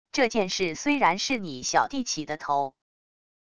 这件事虽然是你小弟起的头wav音频生成系统WAV Audio Player